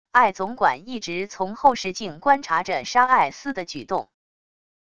艾总管一直从后视镜观察着莎艾思的举动wav音频生成系统WAV Audio Player